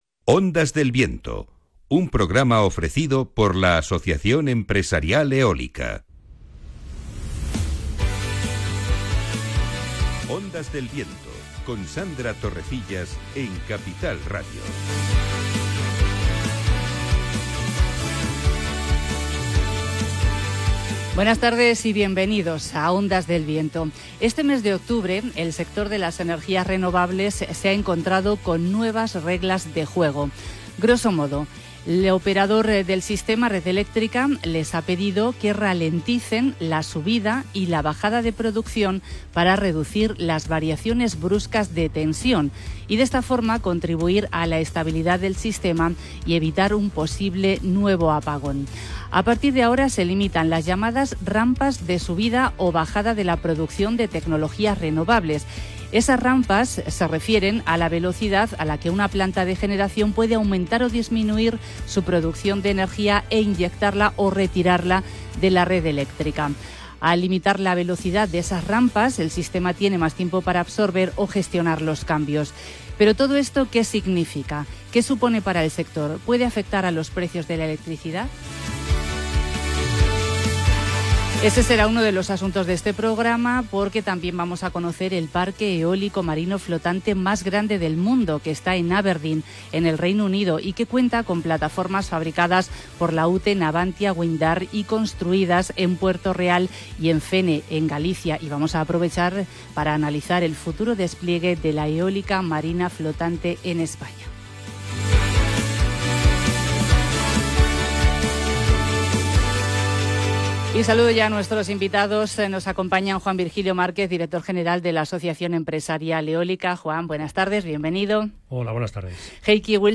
🎙Hoy 14 de octubre hemos emitido un nuevo episodio de Ondas del Viento, el programa radiofónico del sector eólico en la emisora Capital Radio. 🎙Hemos hablado sobre los nuevos cambios introducidos a las renovables para la integración en el sistema eléctrico, si ha habido un posible riesgo de apagón y cómo afecta todo ello al precio de la luz en los consumidores. Además, hemos hablado sobre uno de los mayores parques eólicos marinos flotantes que existen: Kincardine que está en aguas escocesas.